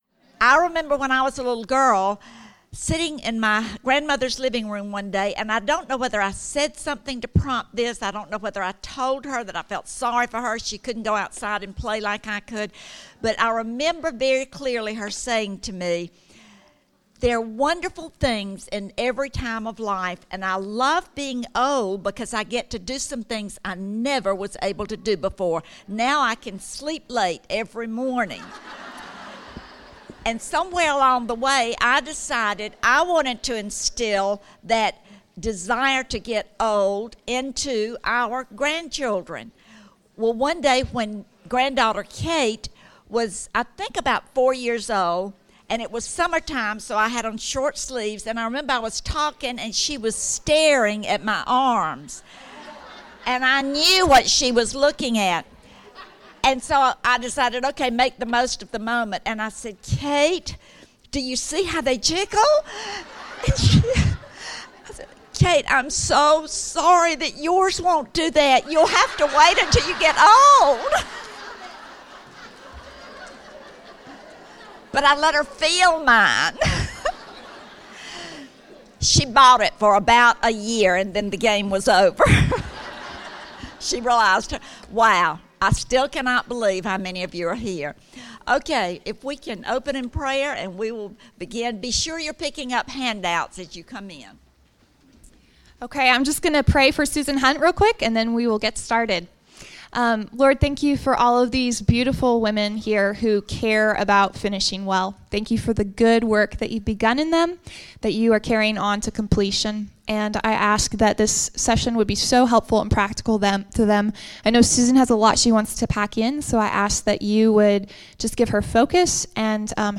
Finishing Strong | True Woman '14 | Events | Revive Our Hearts